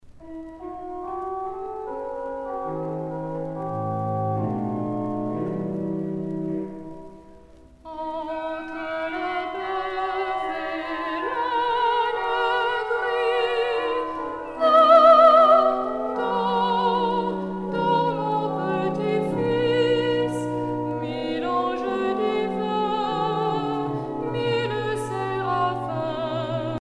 Noël
Artiste de l'album Collège de Montreux (Petit choeur)